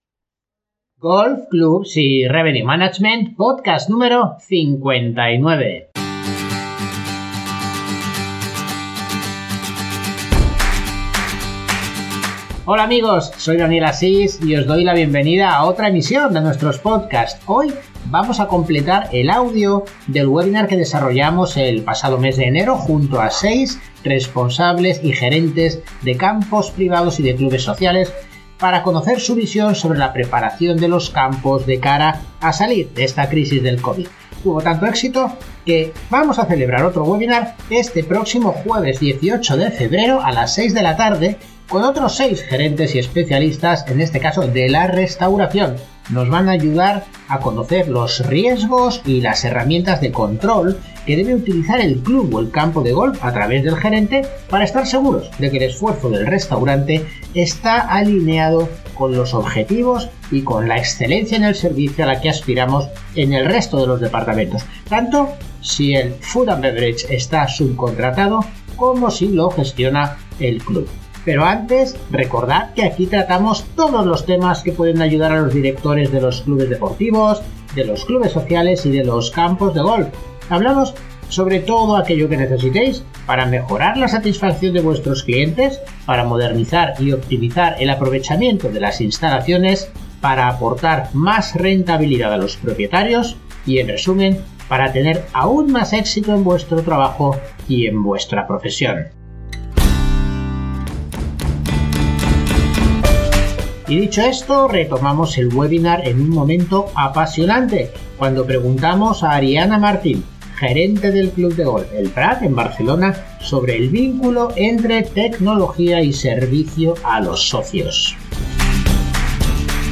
En este podcast concluimos el audio del webinar que emitimos en el mes de enero, con la colaboración de seis gerentes y responsables de campos de golf y de clubes privados enfocando distintos aspectos de la operativa de cara a prepararnos para el término de la pandemia del COVID.
Podcast-59-Webinar-Los-Clubes-y-Campos-de-Golf-tras-el-COVID-parte-3.mp3